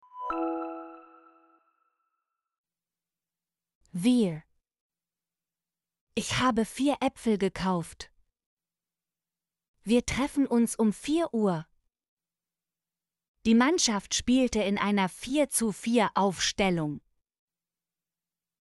vier - Example Sentences & Pronunciation, German Frequency List